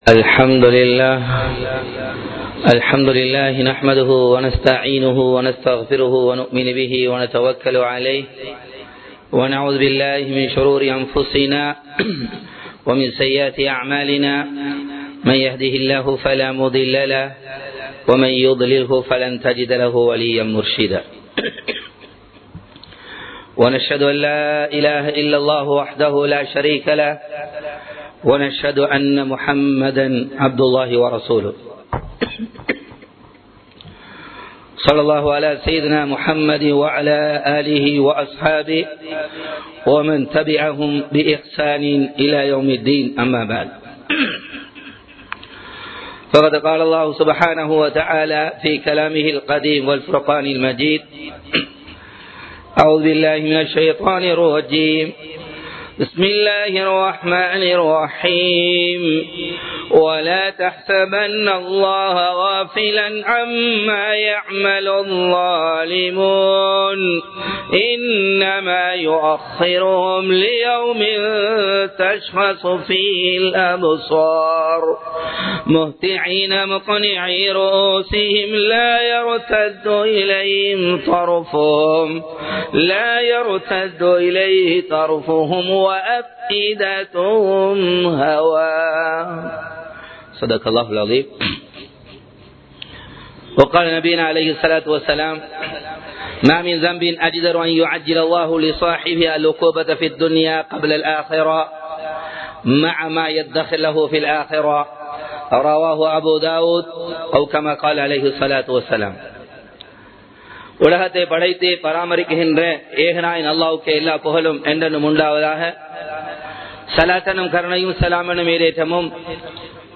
உங்களை அழிக்கும் பாவங்கள் | Audio Bayans | All Ceylon Muslim Youth Community | Addalaichenai
Muhiyadeen Jumua Masjith